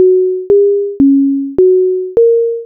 メロディ